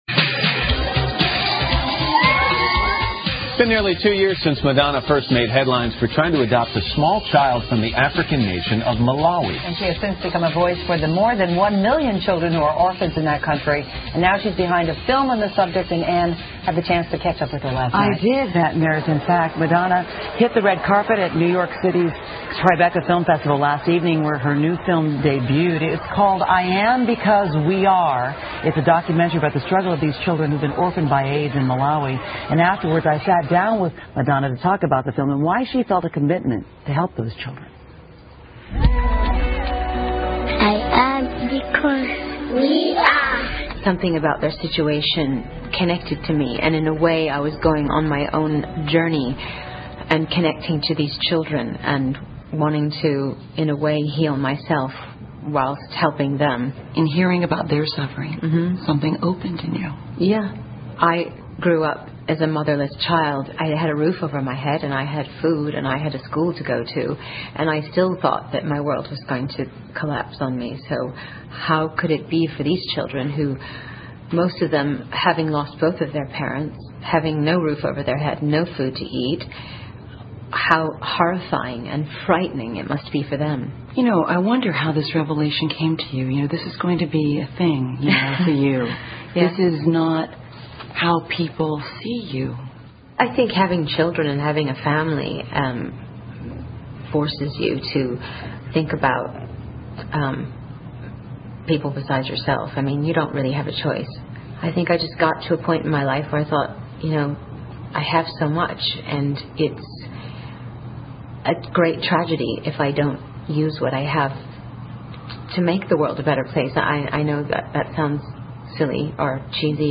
访谈录 2008-05-01&05-03 麦当娜访谈 听力文件下载—在线英语听力室